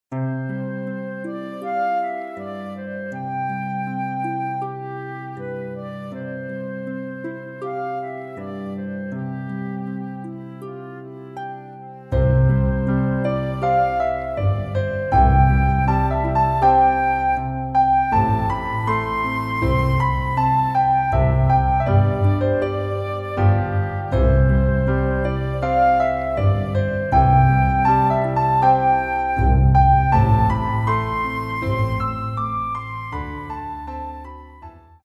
Recueil pour Piano